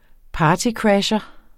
Udtale [ ˈpɑːtiˌkɹaɕʌ ]